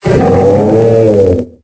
Cri d'Aflamanoir dans Pokémon Épée et Bouclier.